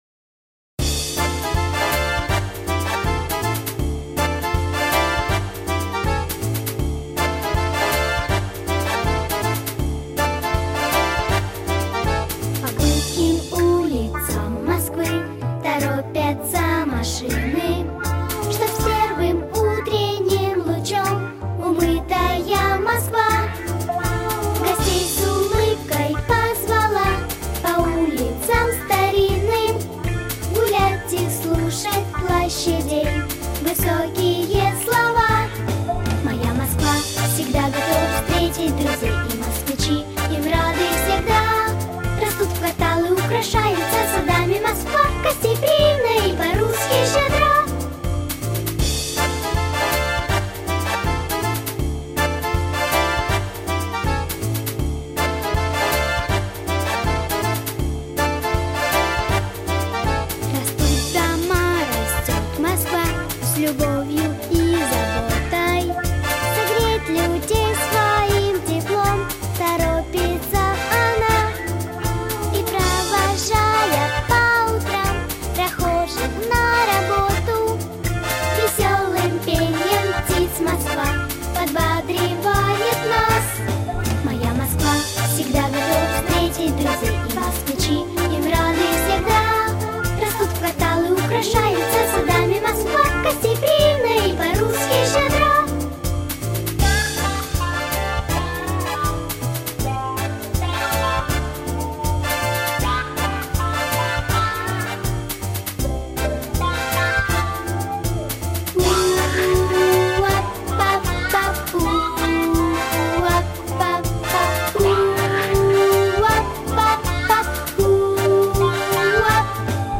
• Категория: Детские песни
Детская песня про Москву, родину, столицу России